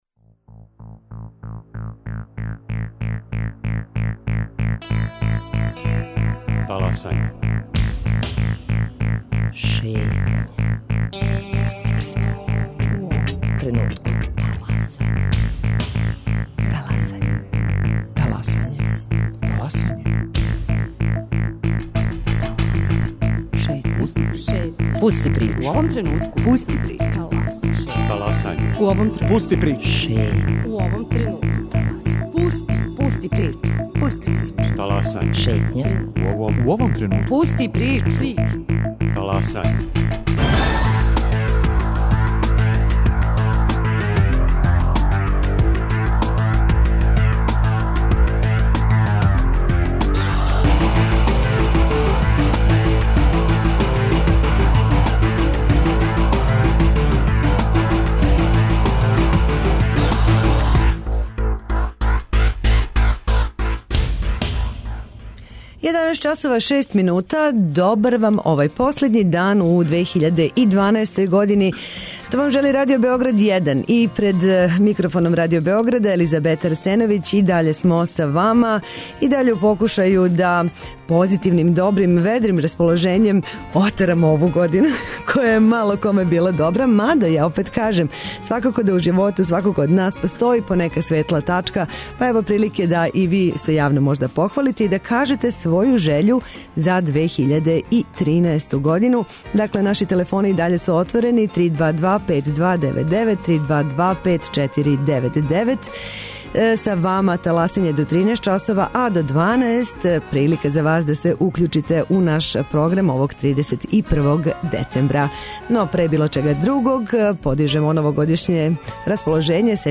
Новогодишњи, ПОЗИТИВНИ, контакт-програм - ИМАМ ЈЕДНУ ЖЕЉУ! Изговорите јавно, преко таласа Радио Београда 1, новогодишњу жељу себи и другима!